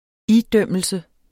Udtale [ ˈiˌdœmˀəlsə ]